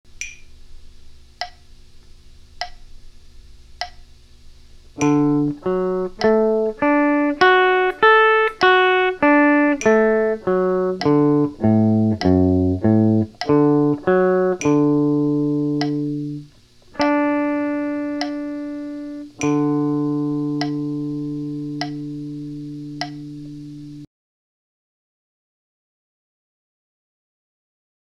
Here is a C form arpeggio:
3. Make it rhythmically complete by ending on either beat 1 or 3.  1 being the strongest.